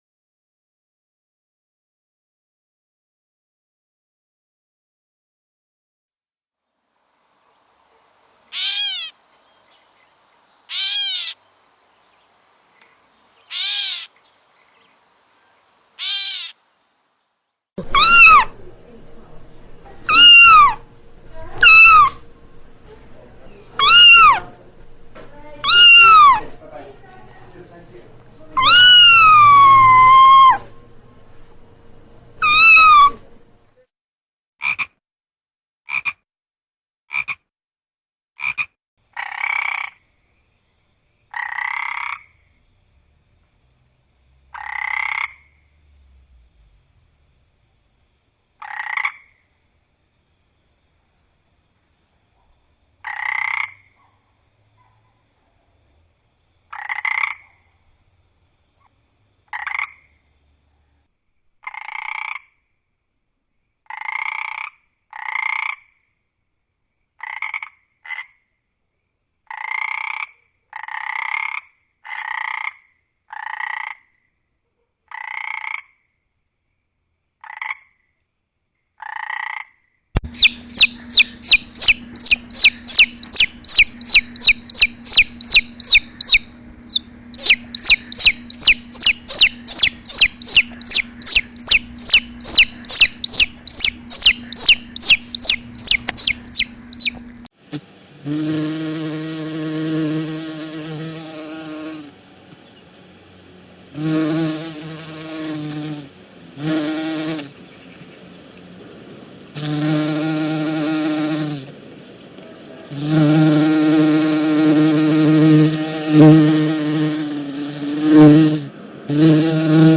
Sounds to attract the attention of dogs and cats, download and listen online
• Category: Sounds that dogs and cats like
• Quality: High